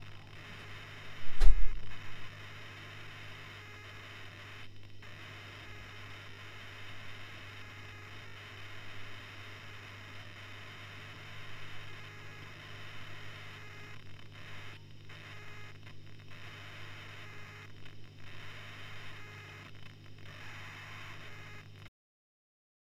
Traynor YBA 200 raschelt und knistert im Leerlauf
Moinsen , hatte zwei Wochen meinen Amp nicht angefaßt , heute wieder angeschlossen , und dann höre ich dieses fürchterliche Grundknistern , ein raschelnes teilweise ab und anschwellendes Geräusch.
Wenn ich spiele funktioniert alles , aber das Geräusch ist die ganze Zeit zu hören , und wird auch wenn Master hoch , mit lauter...